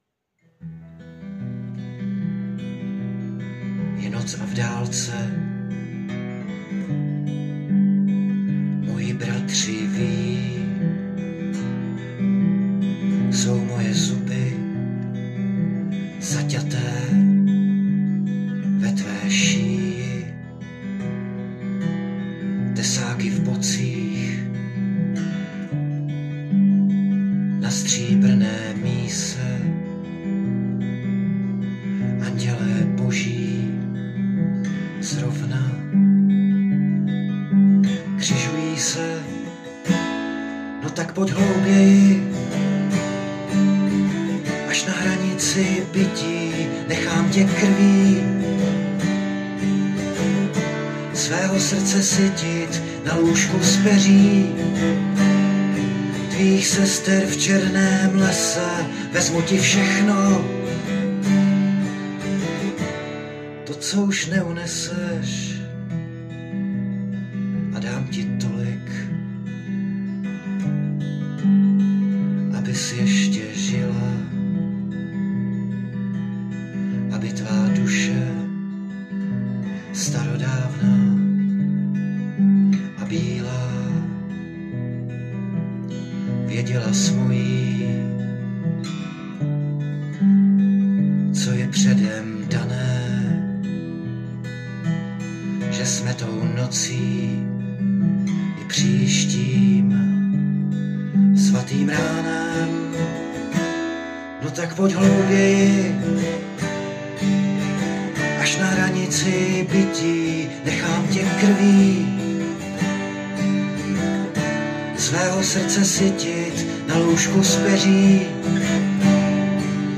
Ale je pro mě tak důležitá, že jsem ji zhudebnil cestou jedna kytara a muž.
těm novým strunám to fakt jde!:) ...krásný, moc...*